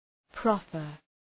Προφορά
{‘prɒfər}